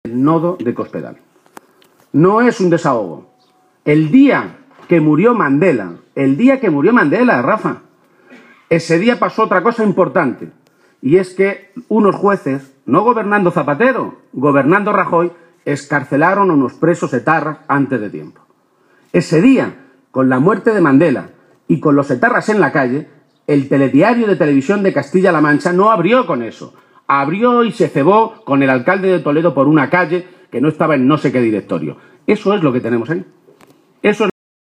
El secretario general del PSOE de Castilla-La Mancha, Emiliano García-Page, ha protagonizado esta tarde un acto electoral en la localidad toledana de Illescas en el que ha dicho que la campaña empieza a ir muy bien para el PSOE y ha animado a oos militantes, simpatizantes y votantes socialistas a seguir “porque a nosotros no nos van a quitar la moral Gobiernos como los de Rajoy y Cospedal, que no tienen moral”.